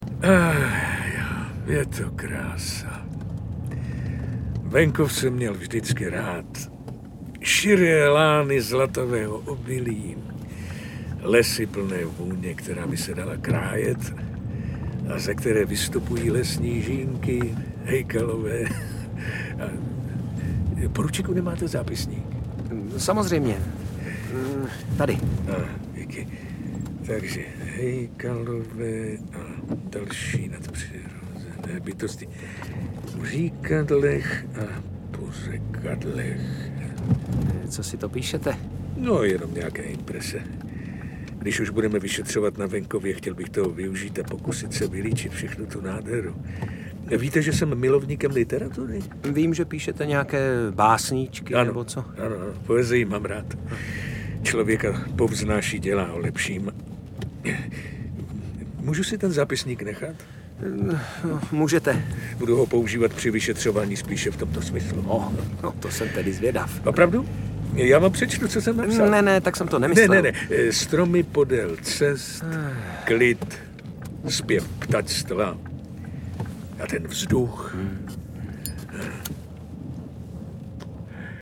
Audiokniha
Čte: Martin Písařík, Viktor Preiss
Vyšetřování vedou kapitán Bernard (Viktor Preiss) a poručík Bejček (Martin Písařík).